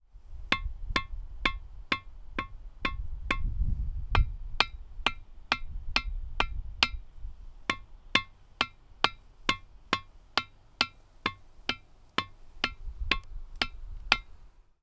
Type: Klangstein